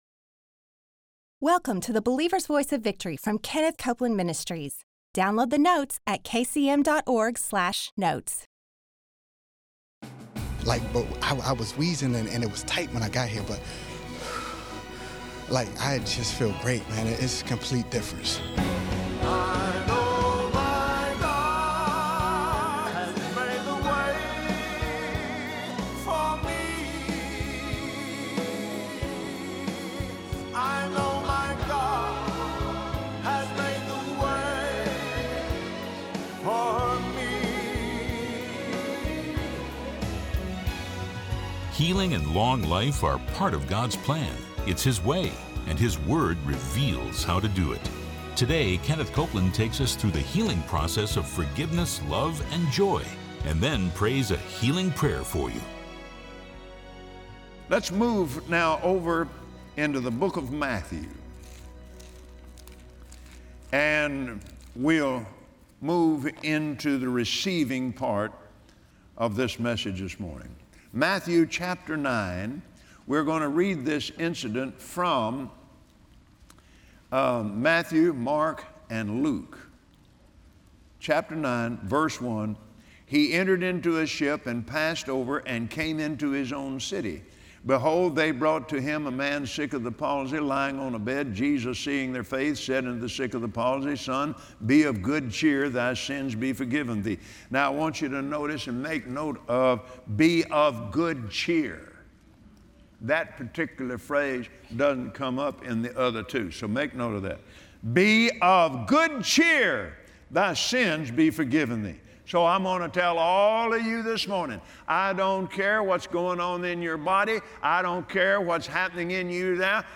Watch Kenneth Copeland on Believer’s Voice of Victory share examples of the process Jesus used in the Bible to heal people. It worked then, and it still works today!